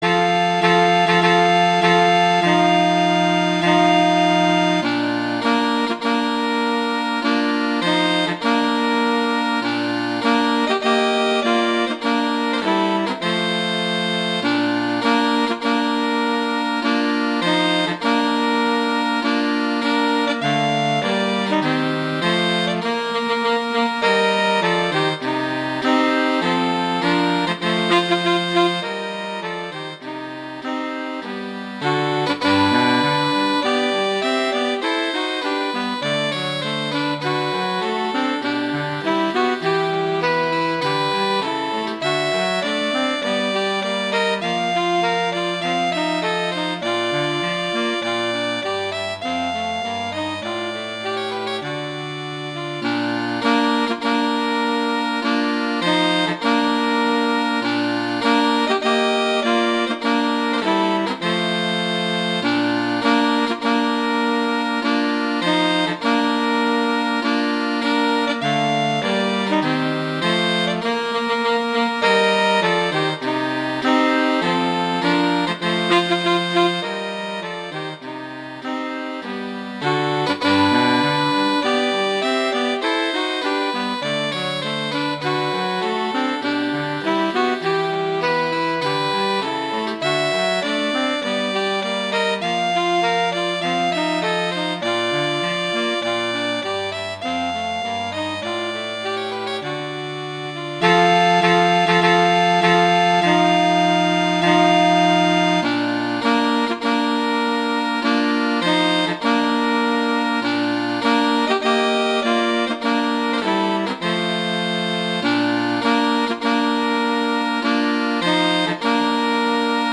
Instrumentation: saxophone trio
arrangements for saxophone trio
tenor saxophone:
baritone saxophone: